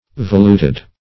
Voluted \Vo*lut"ed\, a.